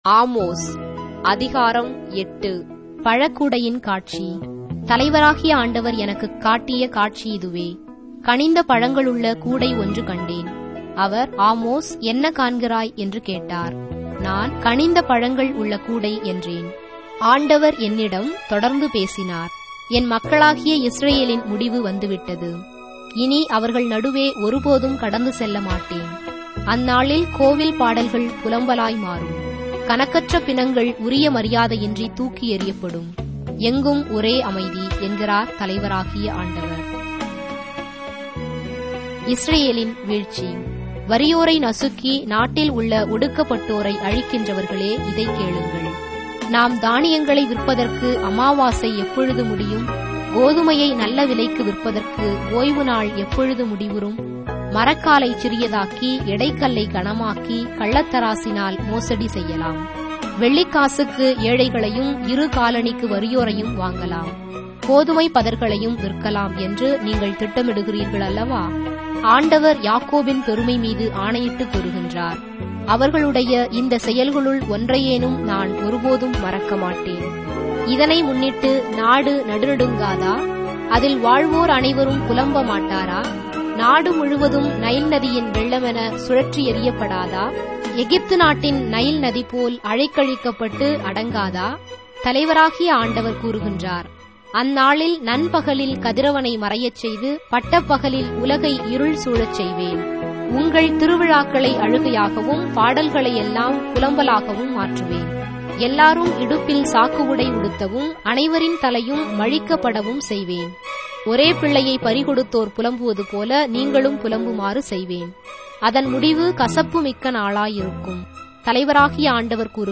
Tamil Audio Bible - Amos 8 in Ecta bible version